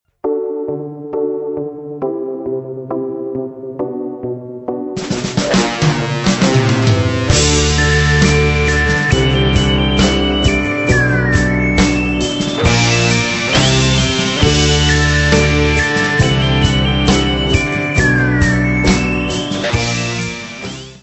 Music Category/Genre:  Pop / Rock